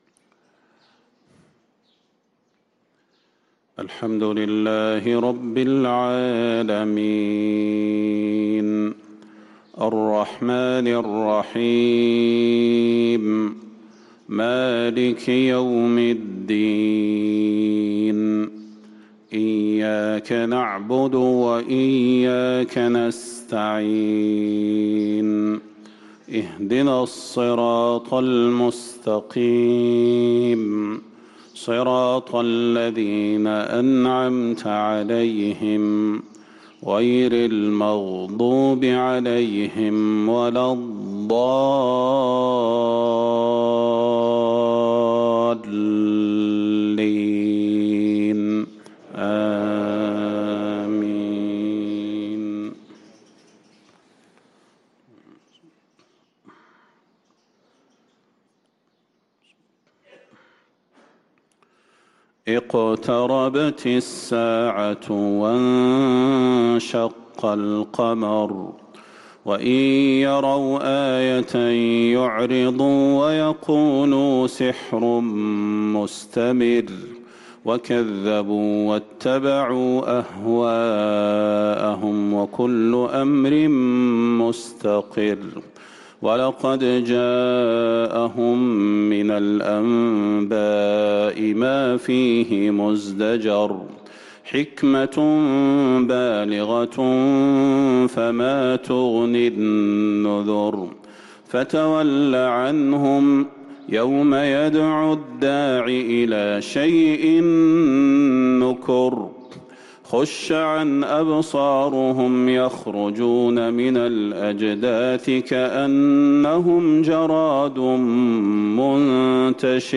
صلاة الفجر للقارئ صلاح البدير 27 جمادي الأول 1445 هـ
تِلَاوَات الْحَرَمَيْن .